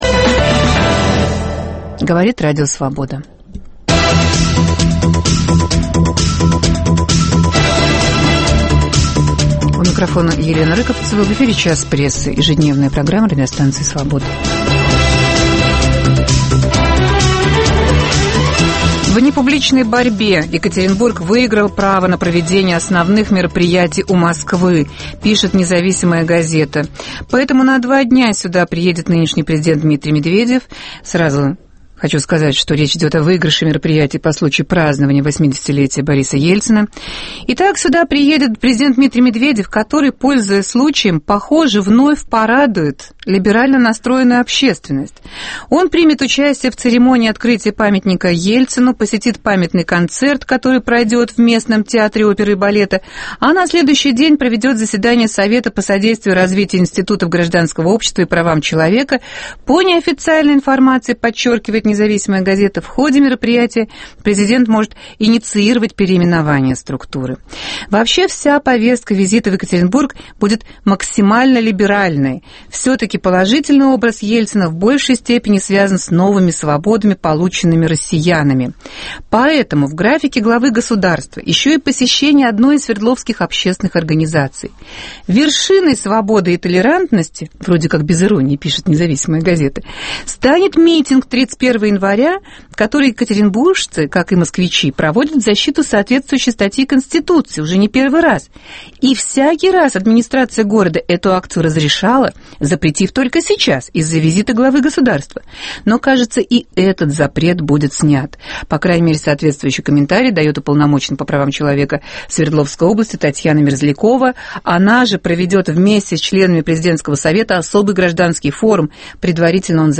Большой график торжеств в Москве и Екатеринбурге намечен к 80-летнему юбилею первого президента РФ. Оцениваем эти планы вместе с гостями студии